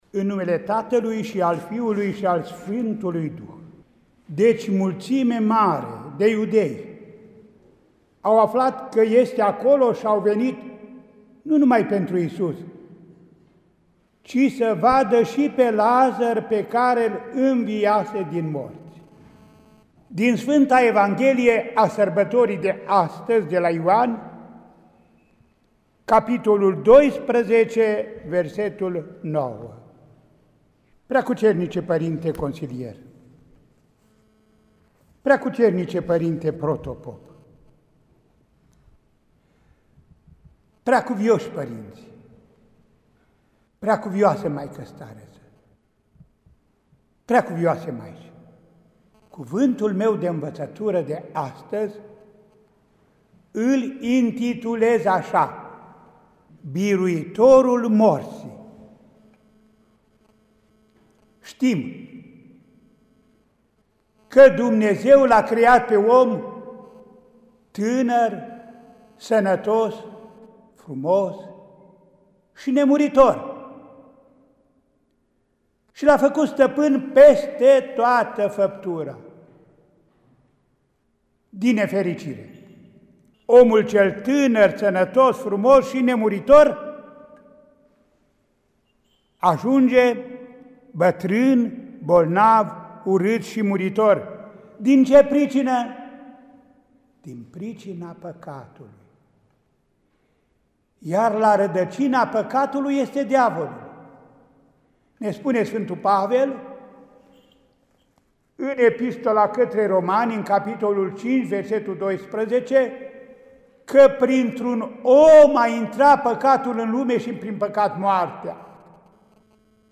Mitropolitul Andrei – Predică la Praznicul Intrării Domnului în Ierusalim (Duminica Floriilor), 12 aprilie 2020